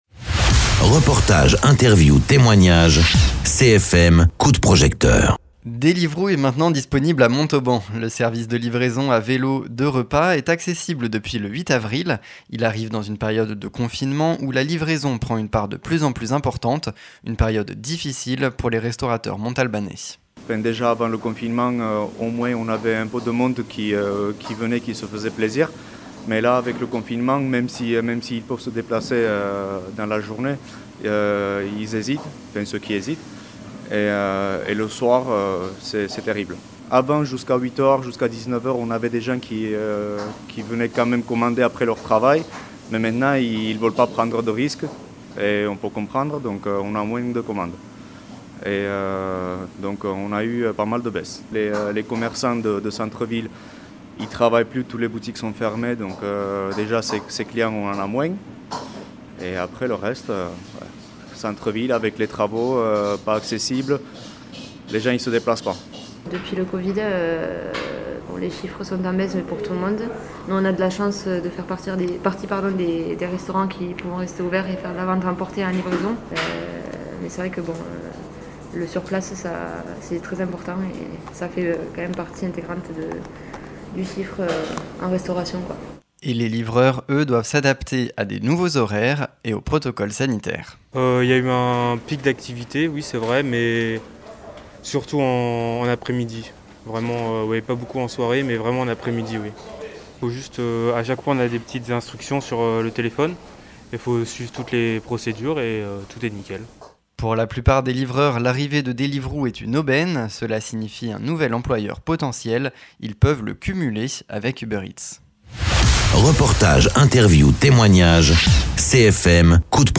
Invité(s) : Restaurateurs et livreurs montalbanais